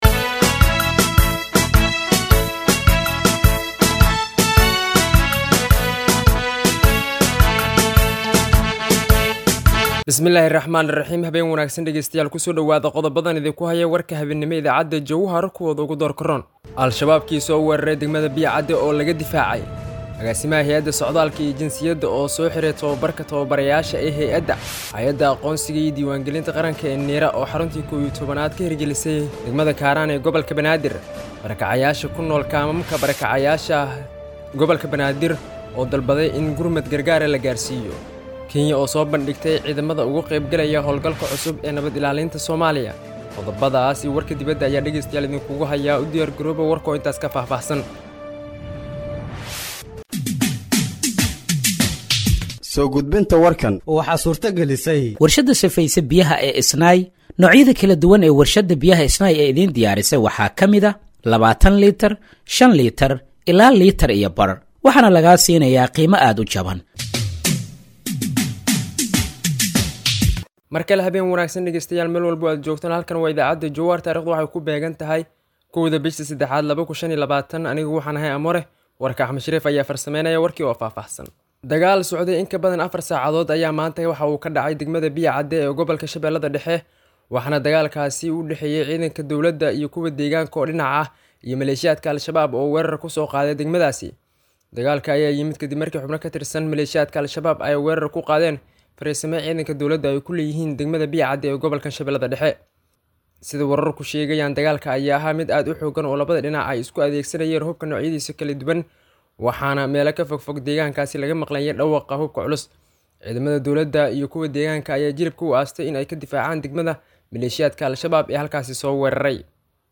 Dhageeyso Warka Habeenimo ee Radiojowhar 01/03/2025